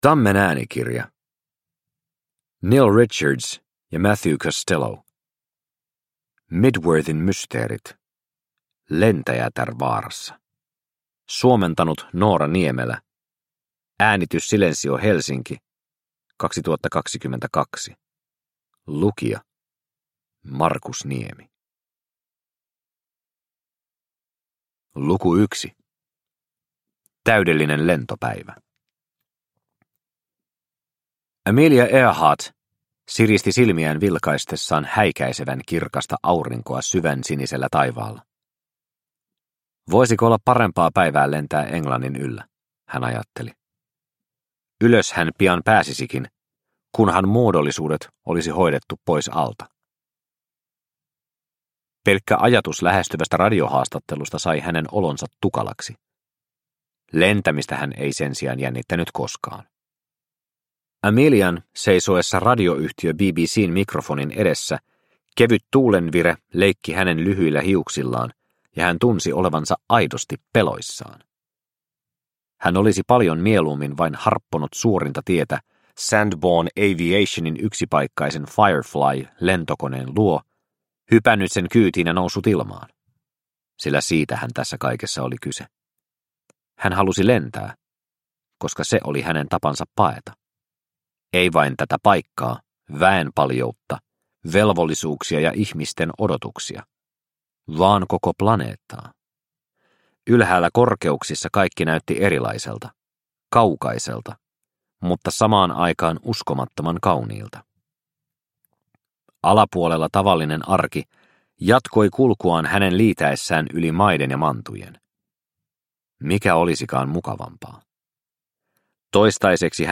Mydworthin mysteerit: Lentäjätär vaarassa – Ljudbok – Laddas ner